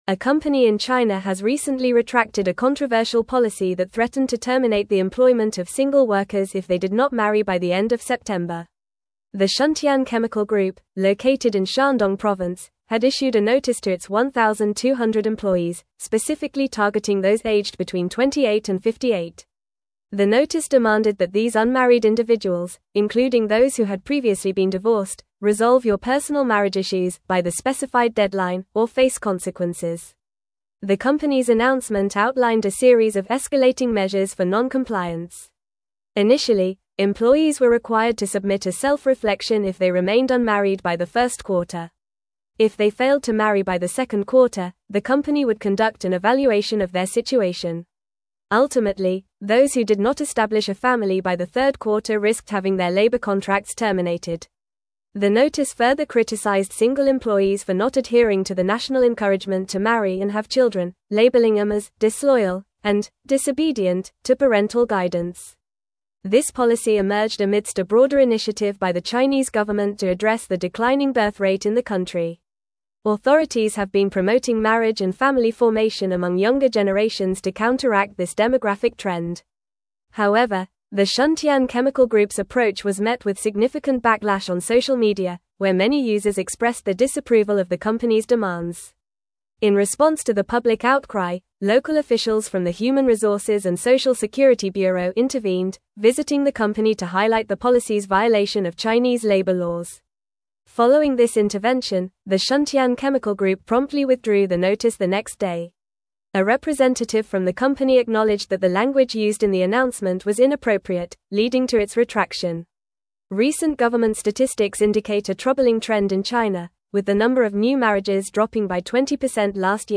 Fast
English-Newsroom-Advanced-FAST-Reading-Company-Faces-Backlash-Over-Controversial-Marriage-Policy.mp3